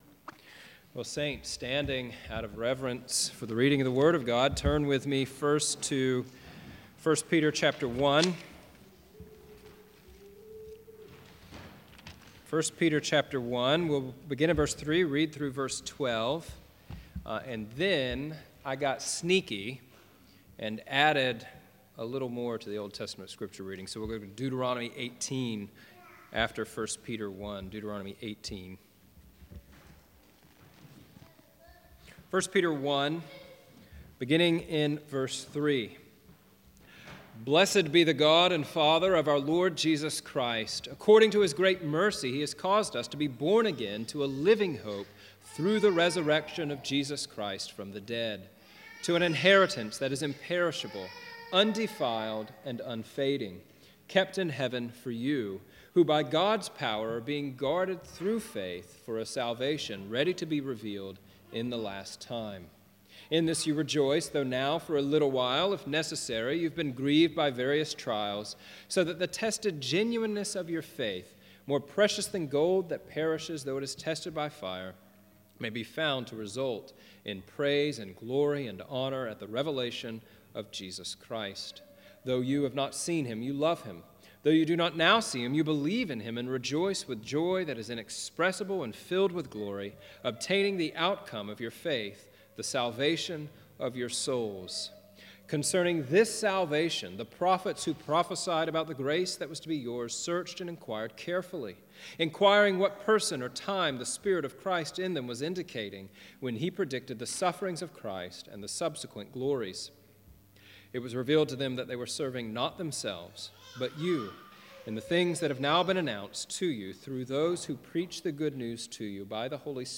1 Peter 1:3-12 Service Type: Sunday Morning « How Can I Know That I Am A Christian?